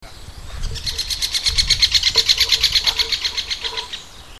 buntspecht2.mp3